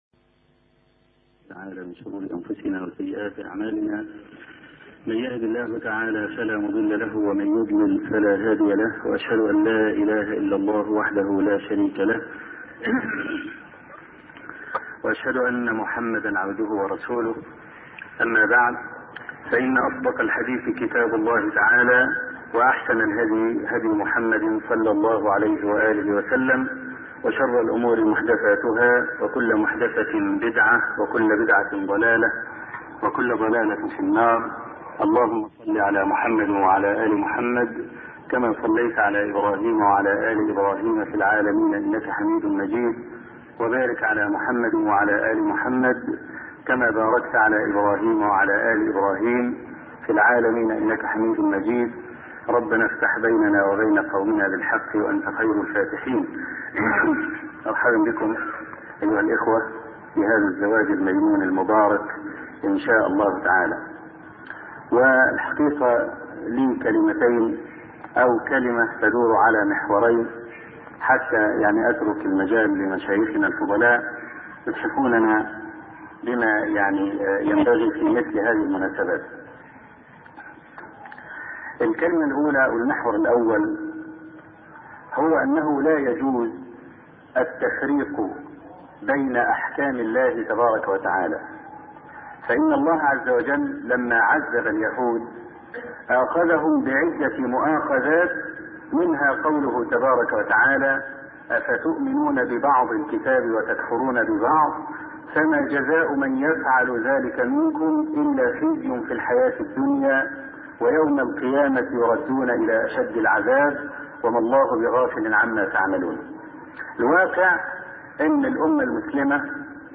حفل زفاف - الشيخ أبو إسحاق الحويني